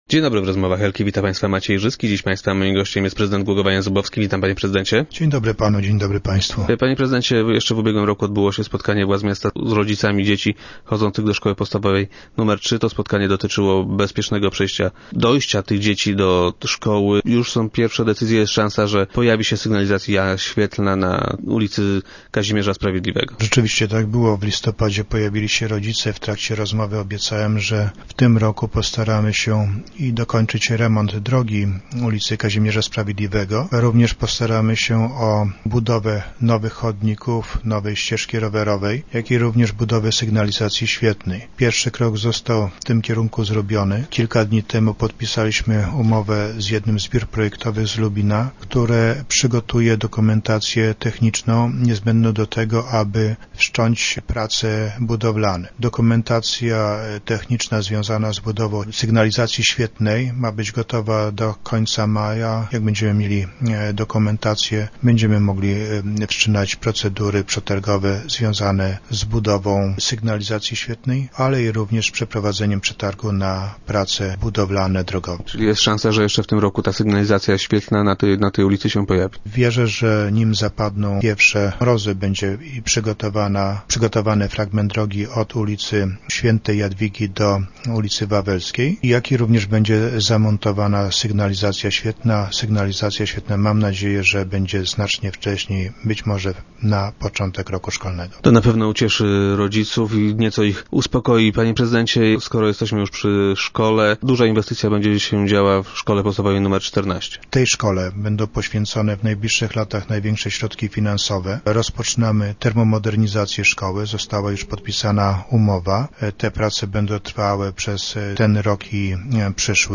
Jak powiedział prezydent Jan Zubowski w dzisiejszych Rozmowach Elki, jeszcze w tym roku powinien rozpocząć się remont ul. Kazimierza Sprawiedliwego i montaż sygnalizacji świetlnej na przejściu dla pieszych.